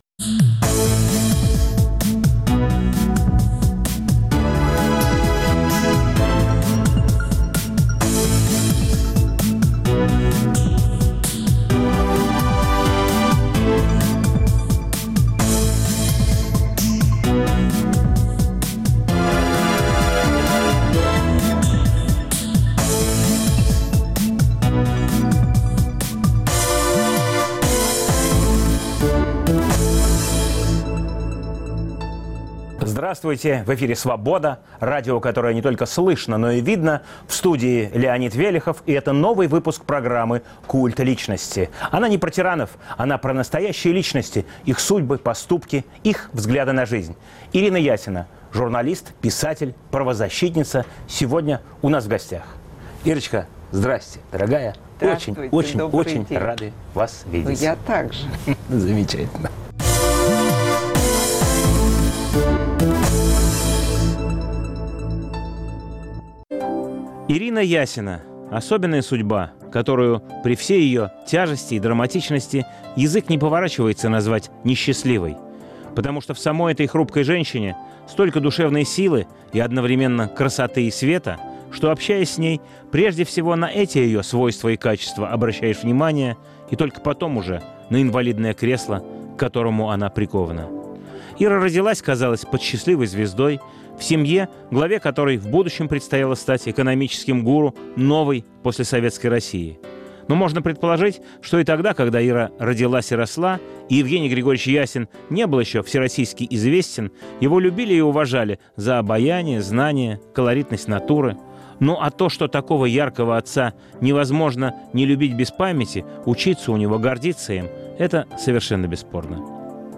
Новый выпуск программы о настоящих личностях, их судьбах, поступках и взглядах на жизнь. В студии общественный деятель, правозащитница Ирина Ясина. Эфир в субботу 14 мая в 18 часов 05 минут Ведущий - Леонид Велехов.